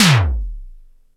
SIMMONS SDS7 13.wav